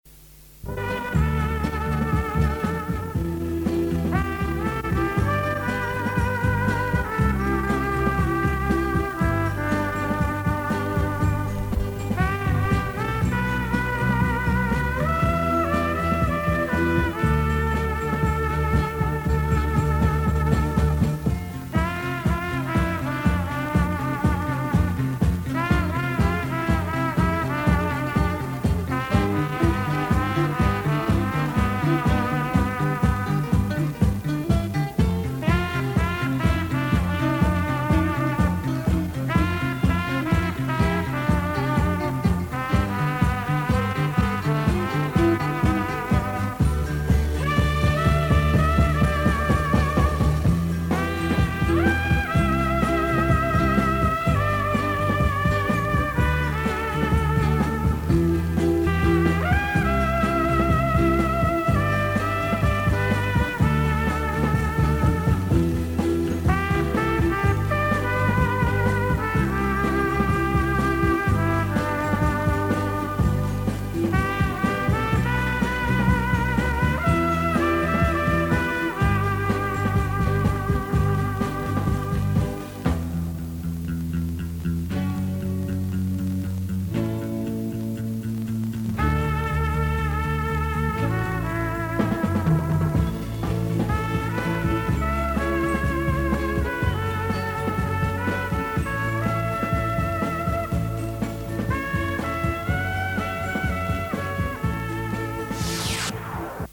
записал с радио в конце 90-х